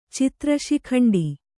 ♪ citra śikhaṇḍi